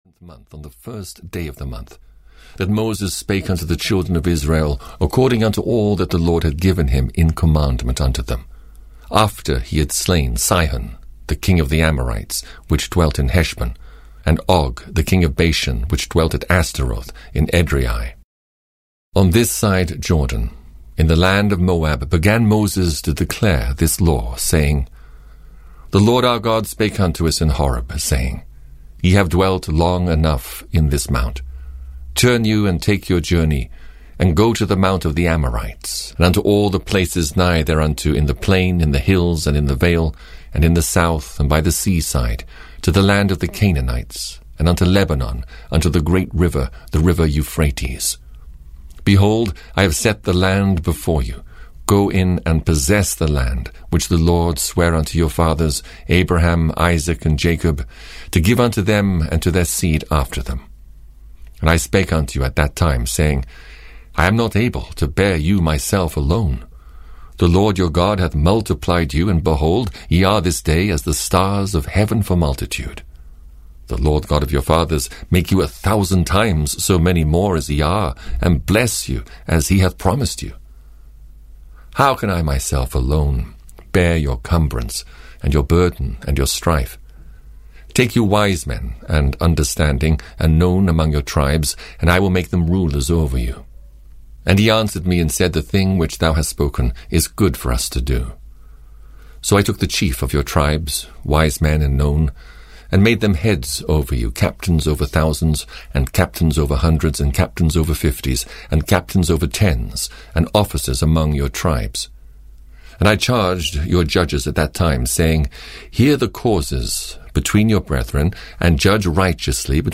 The Old Testament 5 - Deuteronomy (EN) audiokniha
Ukázka z knihy